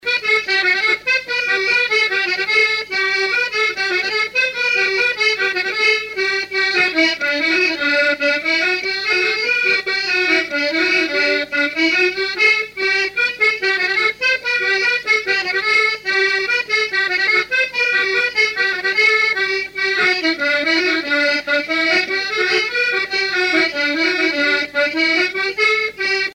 branle : courante, maraîchine
musique à danser à l'accordéon diatonique
Pièce musicale inédite